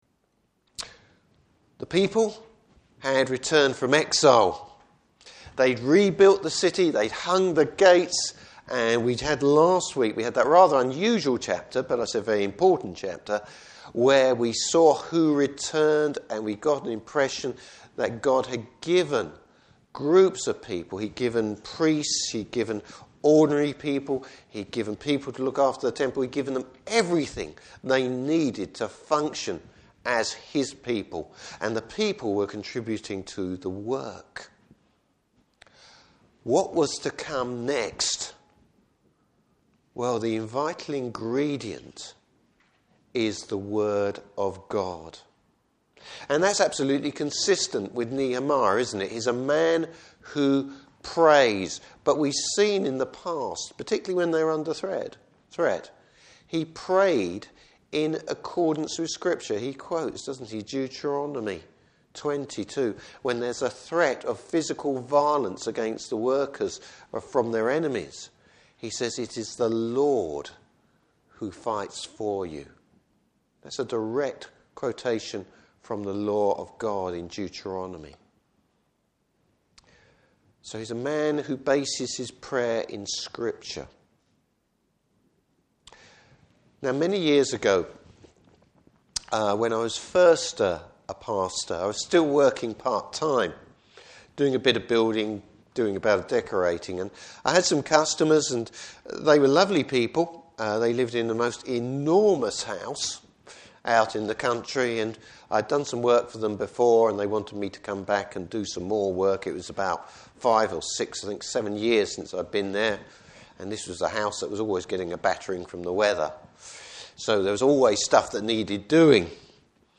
Service Type: Morning Service Why no new revelation is needed in revival!